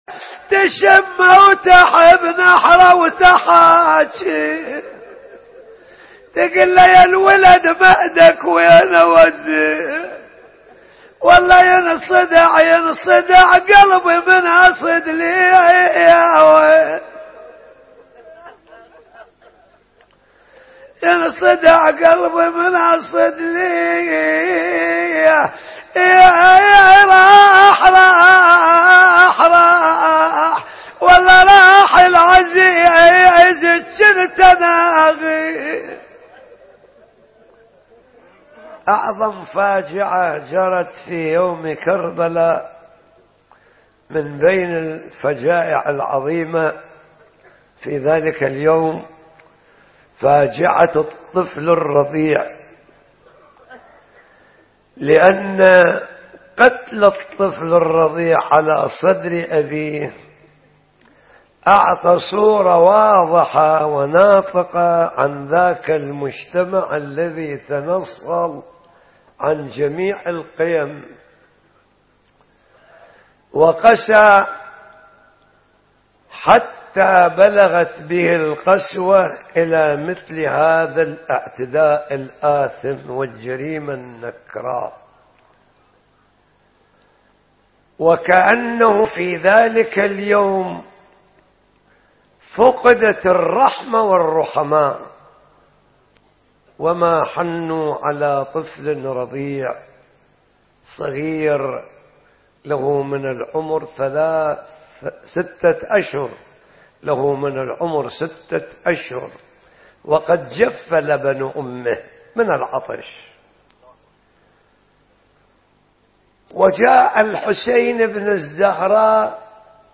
ليلة العاشر محرم 1435 هـ البصرة